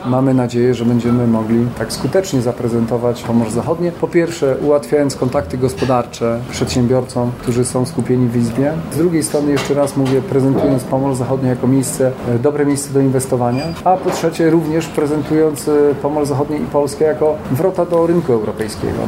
Będzie to doskonała okazja do zaprezentowania potencjału inwestycyjnego i gospodarczego Pomorza Zachodniego – mówił na specjalnej konferencji marszałek województwa Olgierd Geblewicz.